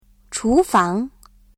語彙詳細 HOME > 文字検索： c > 厨房 chúfáng 文 文法モジュールへリンク 会 会話モジュールへリンク 発 発音モジュールへリンク 厨房 chúfáng ※ご利用のブラウザでは再生することができません。